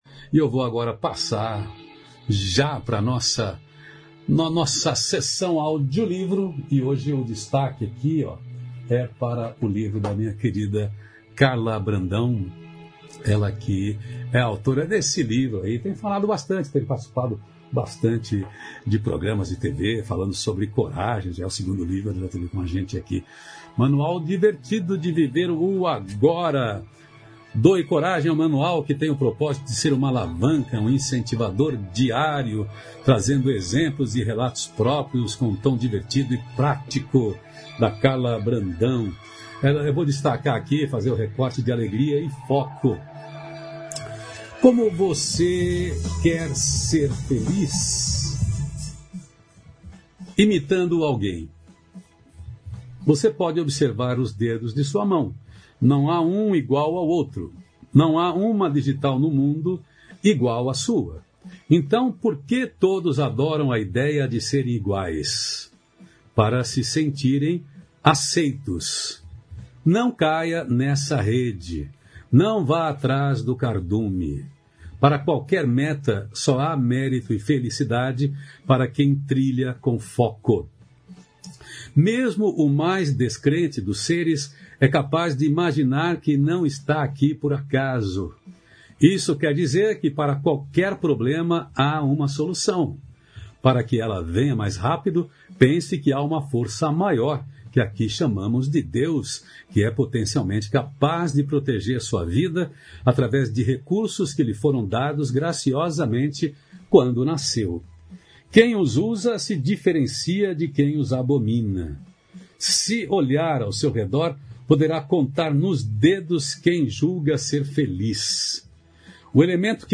Audiolivro